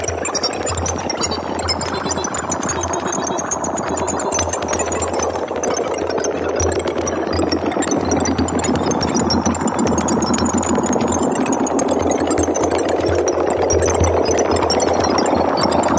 描述：不断变化的环境和噪声环路有利于过渡（120 BPM）
Tag: 循环 环境 处理 毛刺 噪声